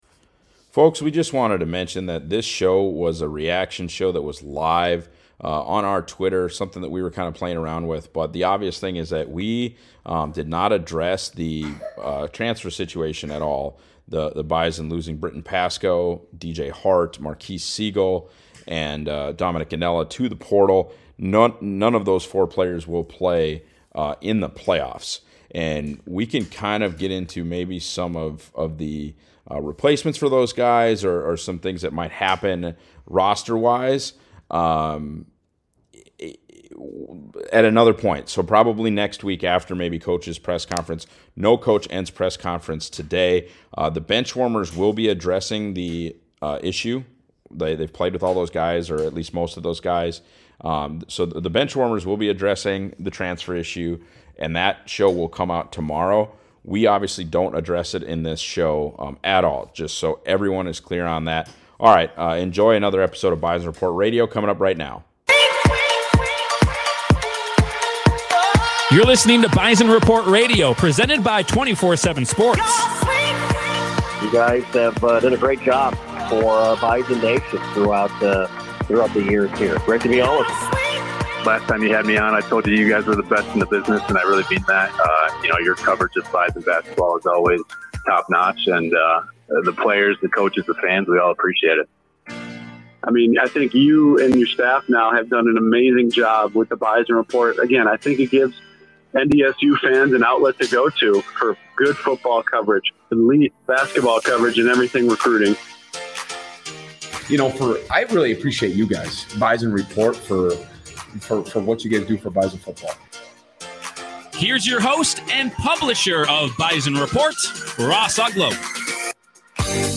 go live on Bison Report Twitter for the first time ever